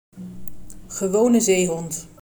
uttale)